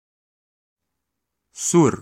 Ääntäminen
IPA : /ɒn/